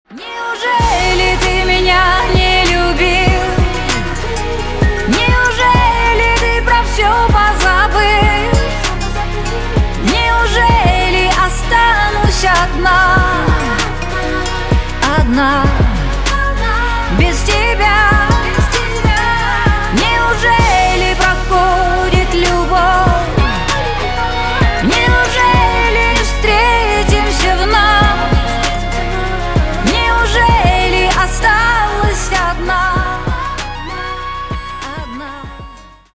на русском грустные про любовь на бывшего